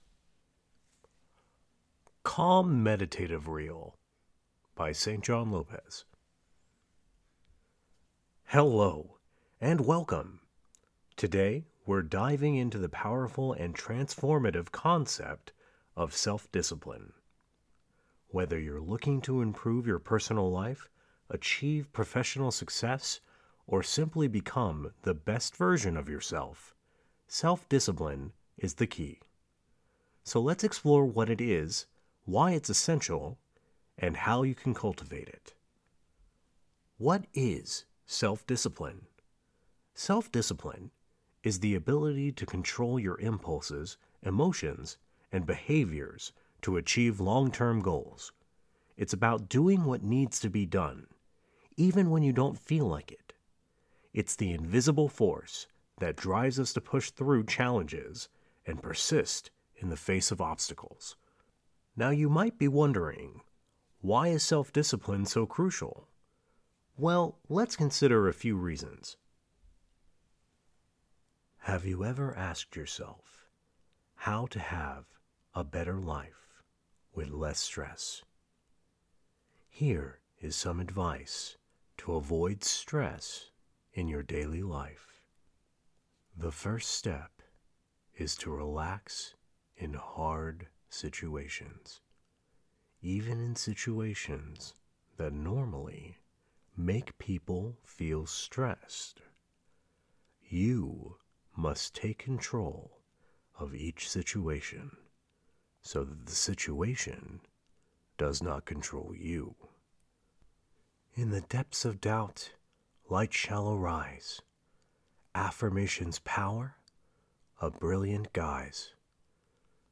Calming Reel (2024) - Serene, Calm, Even-Keeled, Peaceful Reads.
Calming Reel.mp3